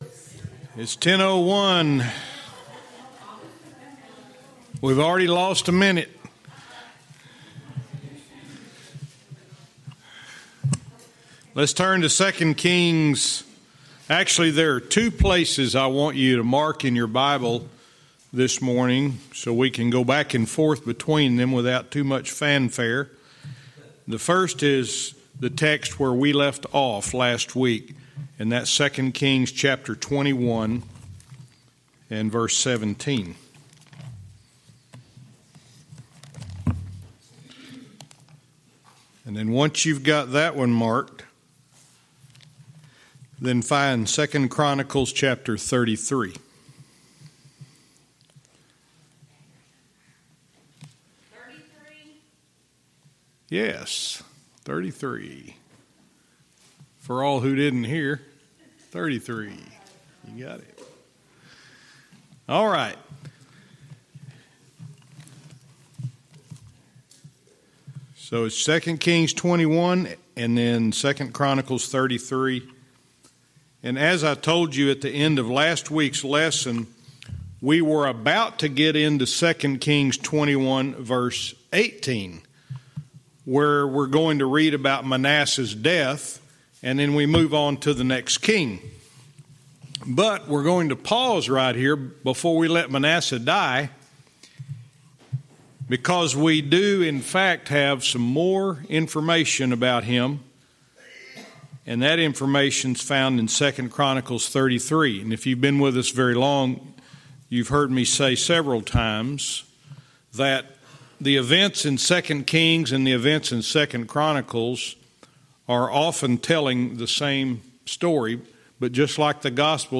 Verse by verse teaching - 2 Kings 21:18 & 2 Chronicles 33:1-12